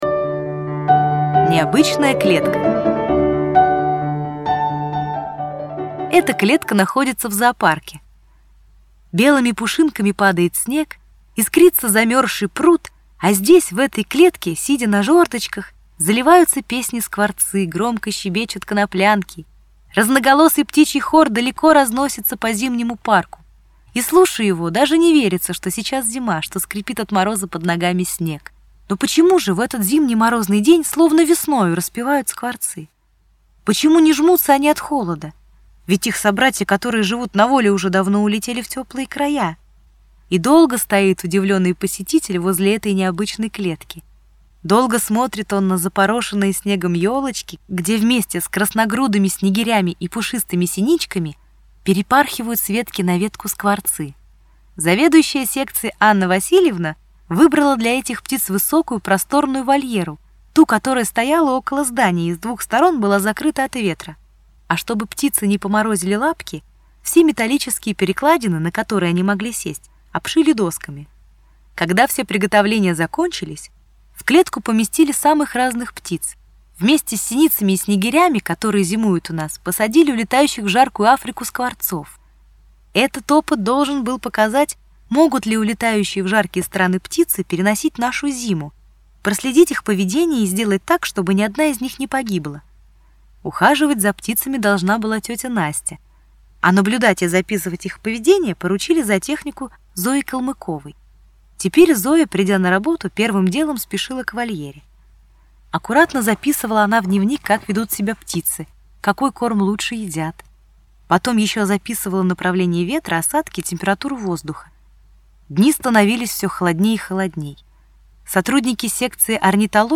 Аудиорассказ «Необычная клетка»